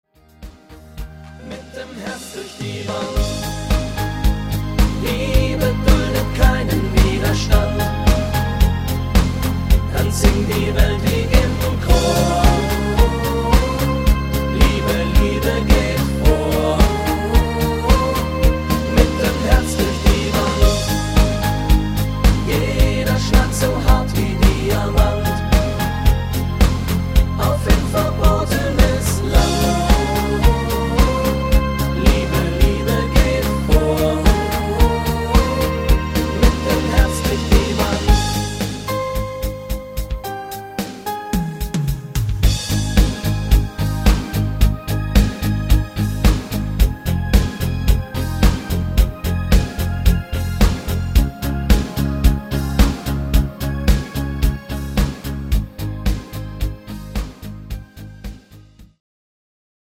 Rhythmus  Medium 8 Beat
Art  Deutsch, Popschlager, Schlager 2010er